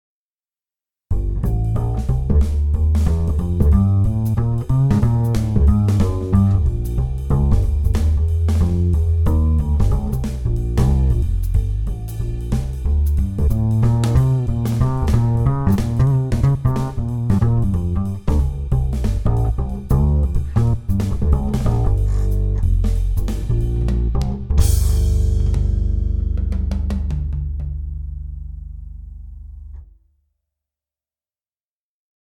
The Manytone Upright Bass includes both a DI soundset and a Mic'd soundset, such that the layering of these soundsets can produce unique tones.
manytone_upright_bass_demo2.mp3